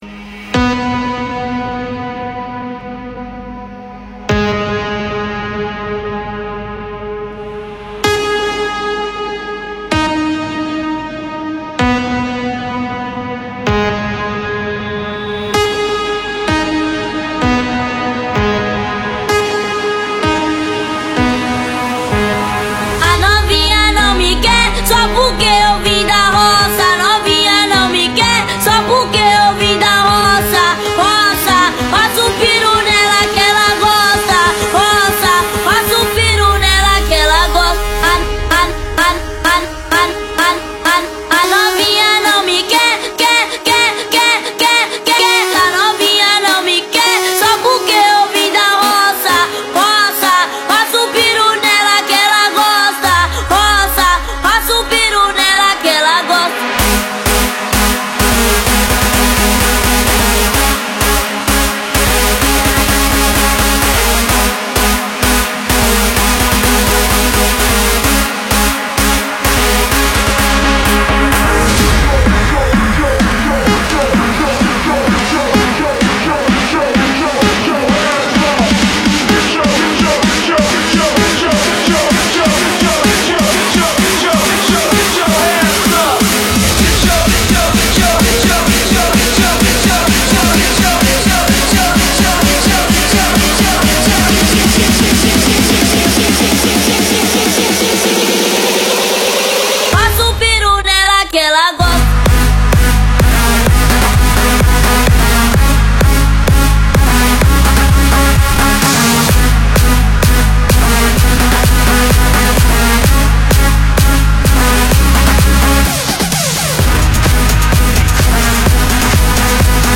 muitos sucessos em versões eletrizantes